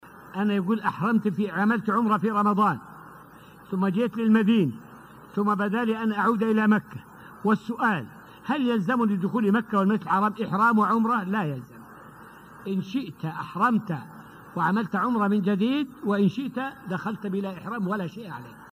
فائدة من الدرس الأول من دروس تفسير سورة البقرة والتي ألقيت في المسجد النبوي الشريف حول معنى الإحرام.